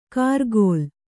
♪ kargōl